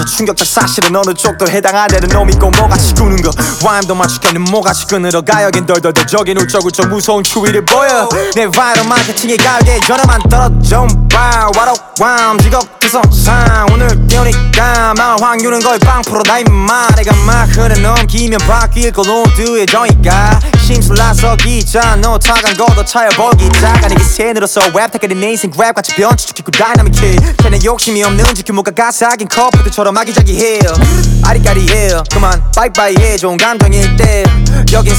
K-Pop Pop Hip-Hop Rap
Жанр: Хип-Хоп / Рэп / Поп музыка